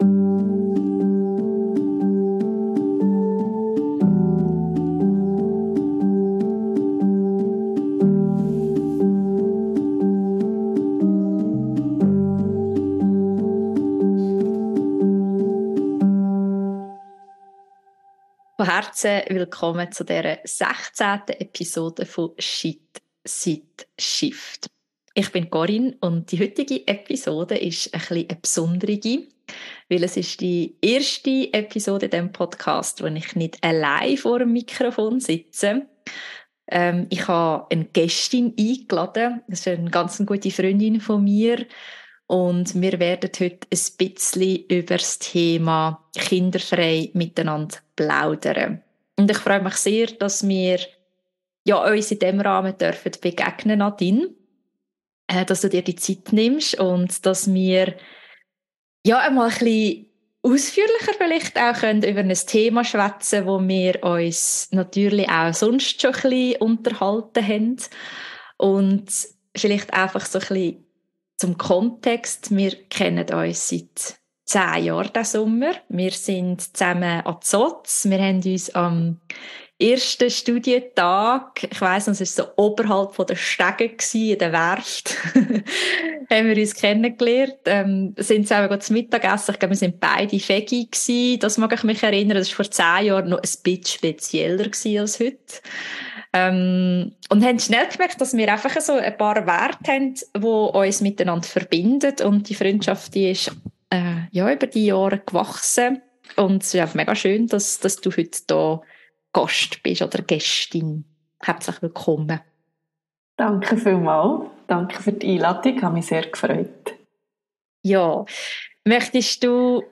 Podcast 016 Kinderfrei Erweiterte Suche 016 Kinderfrei vor 2 Jahren Ein Gespräch zwischen Freundinnen über unsere kinderfreien Wege. 1 Stunde 17 Minuten 62.85 MB Podcast Podcaster Shit?